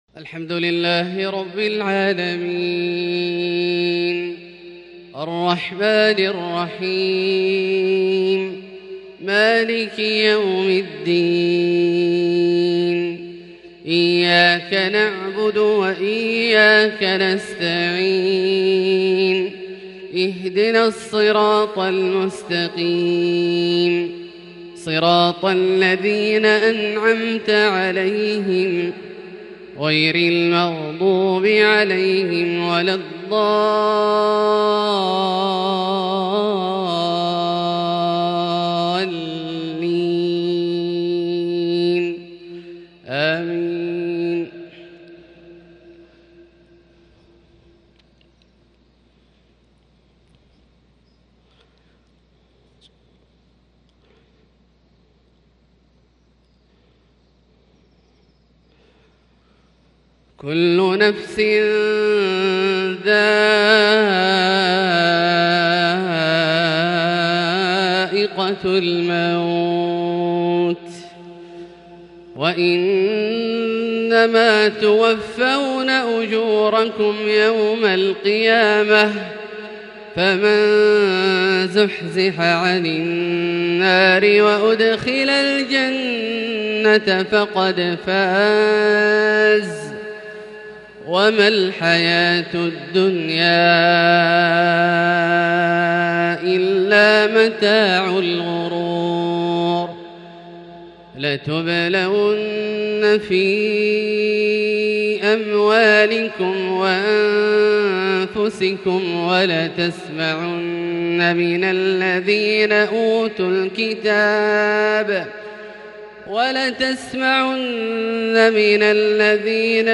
Fajr prayer from Surat Aal-i-Imraan 7-1-2021 > H 1442 > Prayers - Abdullah Al-Juhani Recitations